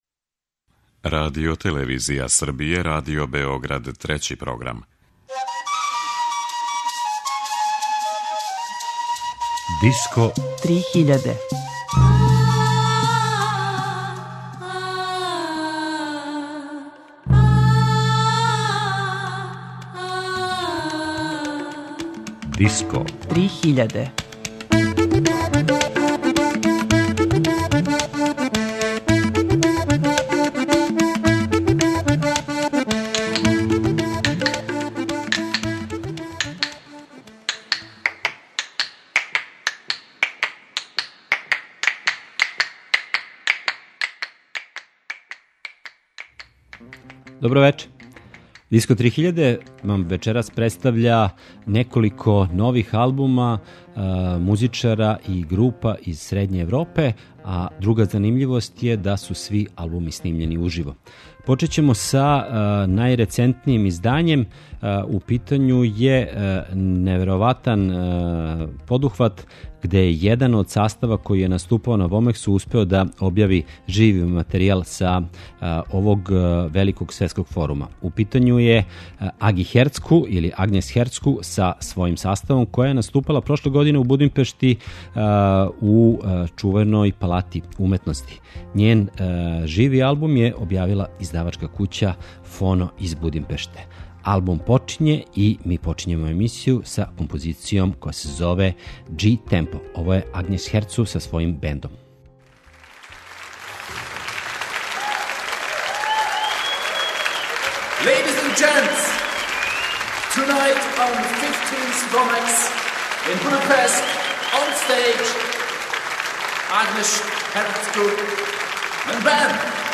У вечерашњој емисији ћемо представити три таква издања, на којима свирају музичари Централне Европе.
И на крају пројекат Три гласа је снимљен на концерту у Чешкој, а певачице и музичари су из Чешке, Мађарске и Израела.